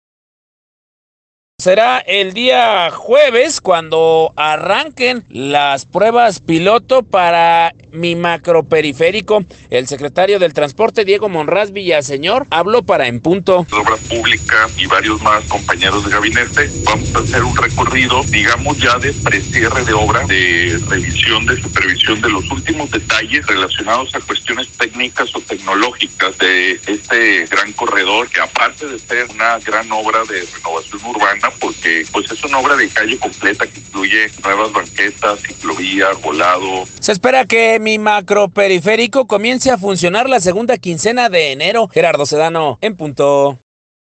Será el día jueves, cuando arranquen las pruebas piloto para Mi Macro Periférico, el secretario de Transporte, Diego Monraz Villaseñor, habló para En Punto.